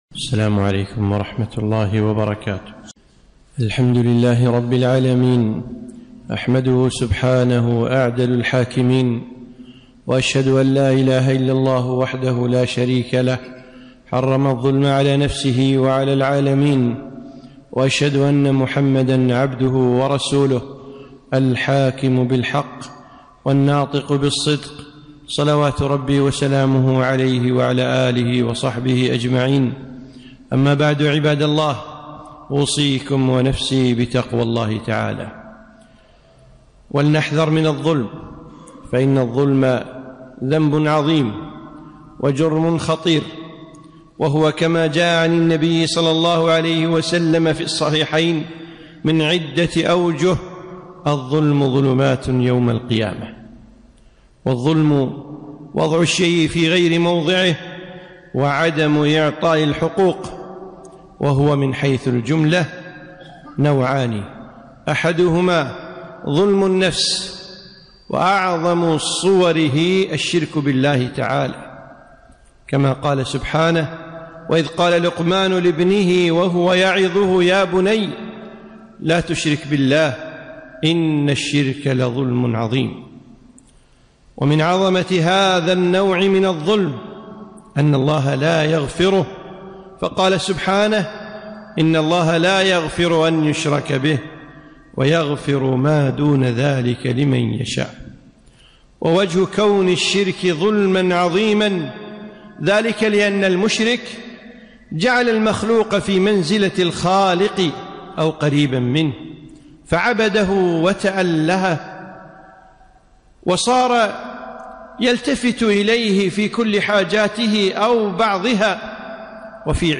خطبة - إياك والظلم